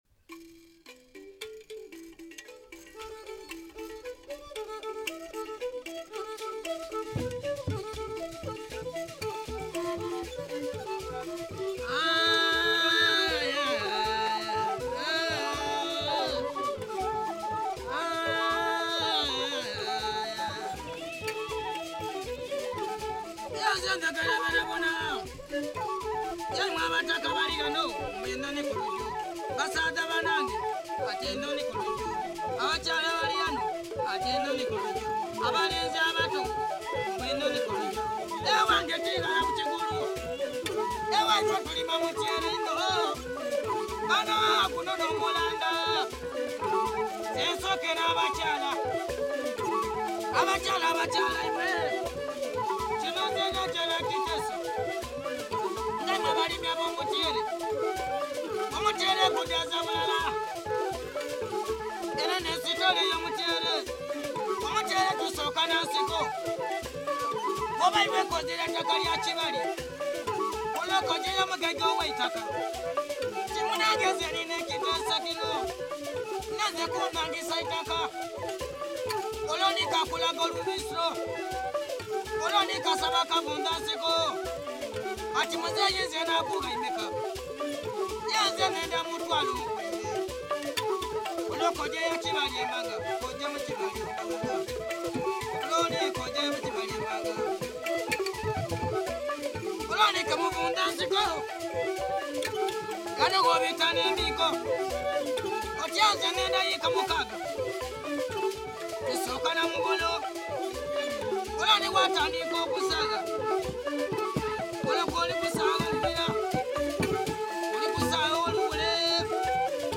[Later, fiddles and flute enter making transcription of the text more difficult.]
featuring enkwanzi (panpipes), flute, fiddle, drums and embaire [xylophone].
budongo
As usual in such songs as this one – Enhonhi kulujo – his budongo leads the way but then is virtually drowned out  – even when no xylophone or drums are used.